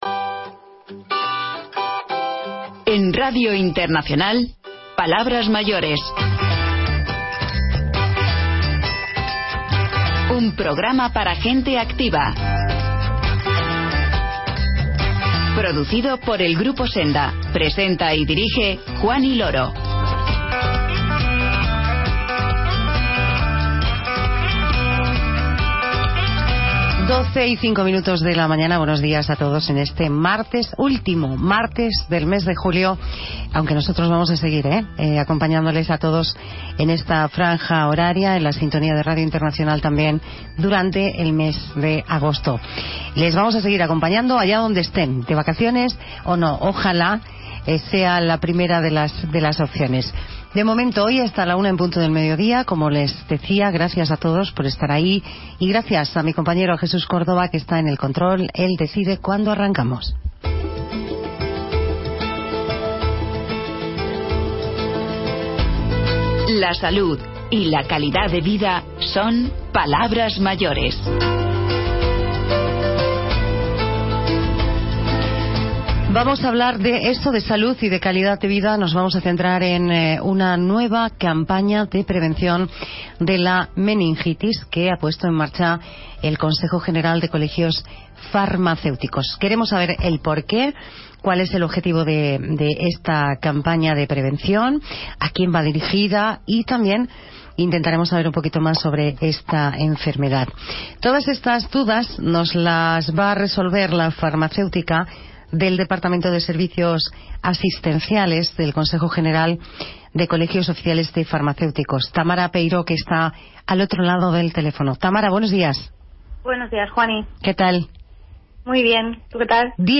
Programa de radio emitido en Radio Internacional